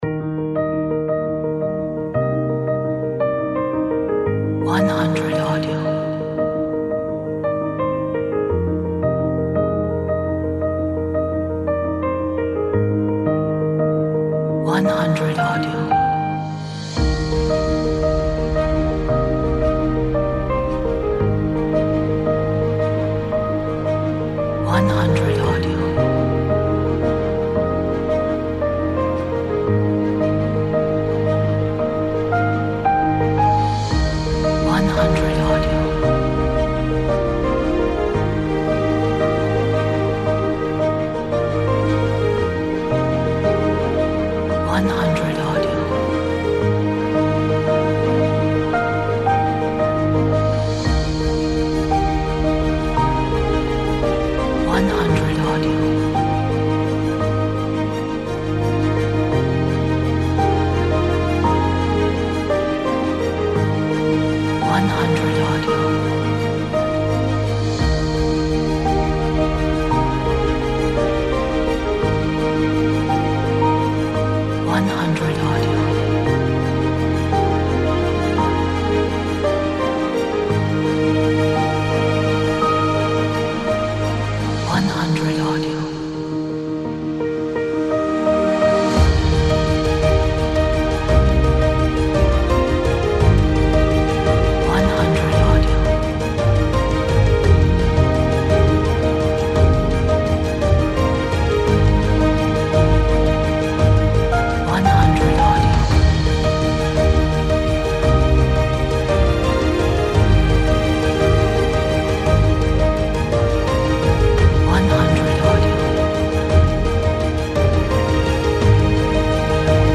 Motivational Positive Piano